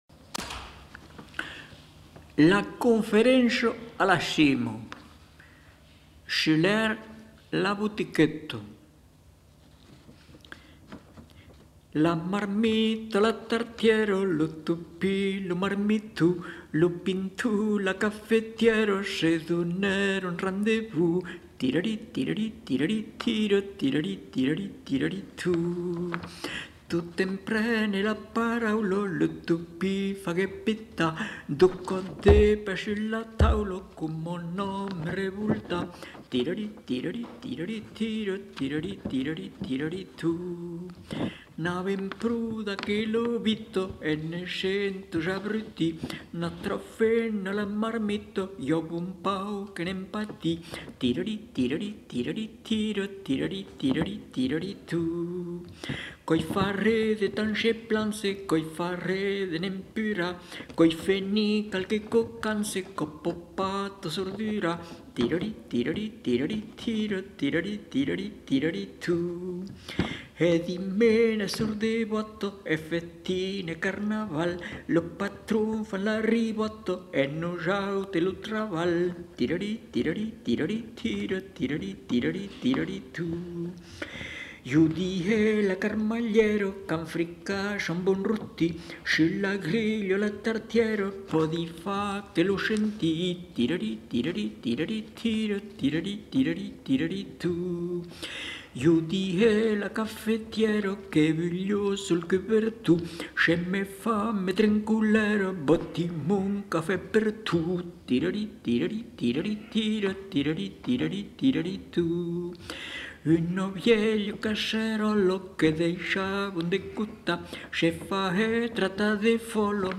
Aire culturelle : Périgord
Lieu : Lolme
Genre : chant
Effectif : 1
Production du son : chanté